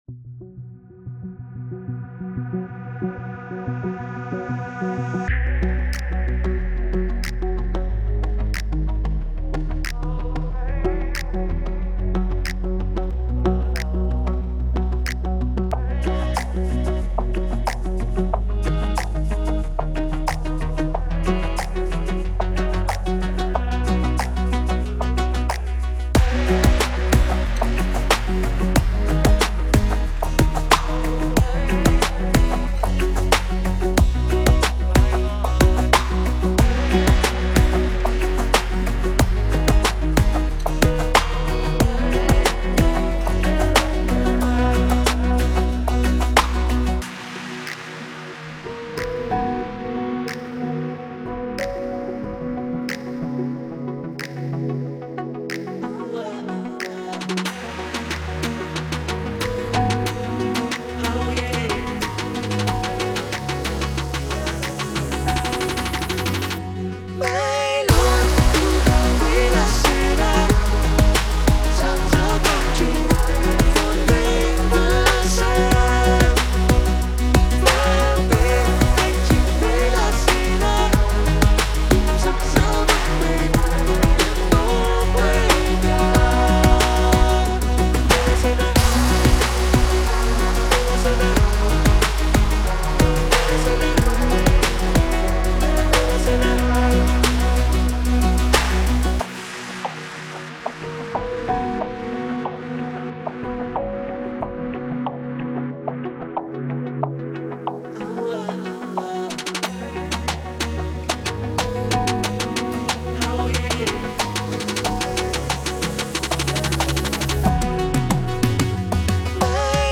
Nhạc Beat
+ Giảm tone (có bè)